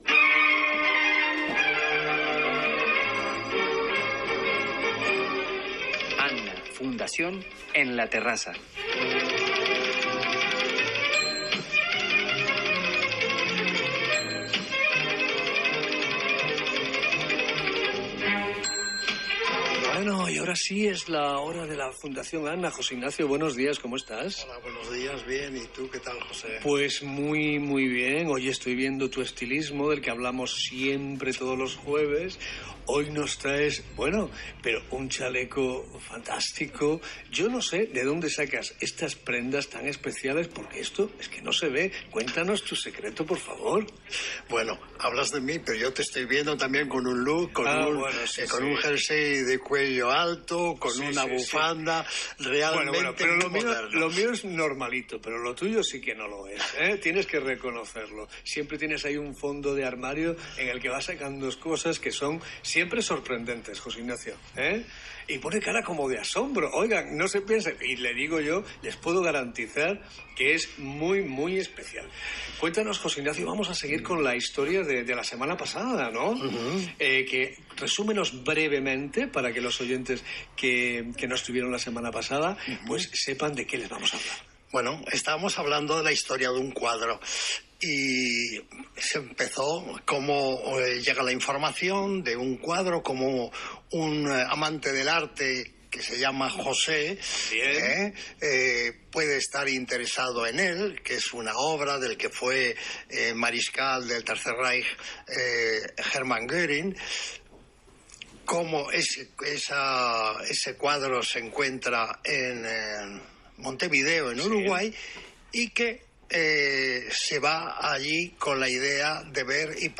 AN-A FUNDACIÓN EN RADIO INTERCONTINENTAL MADRID 95.4 FM hoy jueves